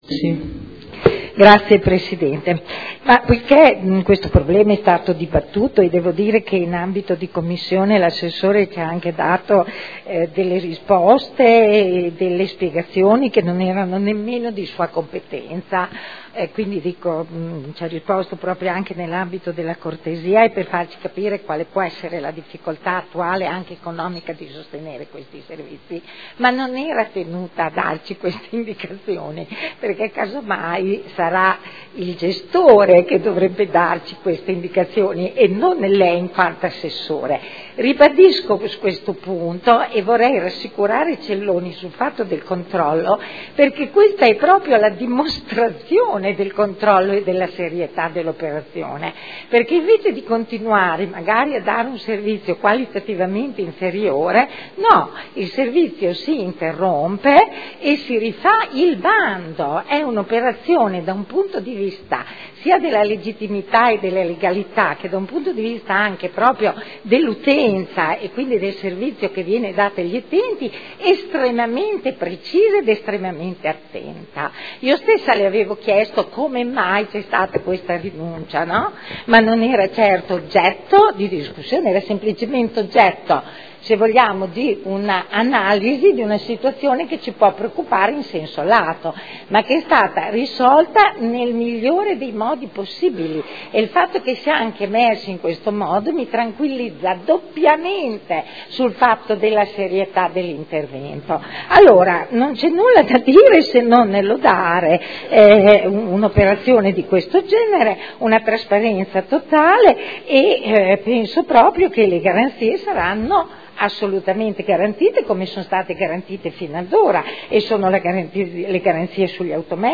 Eugenia Rossi — Sito Audio Consiglio Comunale
Seduta del 05/11/2012. Dibattito su proposta di deliberazione: Linee di indirizzo per l’affidamento del servizio di trasporto disabili alle attività diurne, socio-occupazionali e del tempo libero – Periodo dal 1.3.2013 al 28.2.2015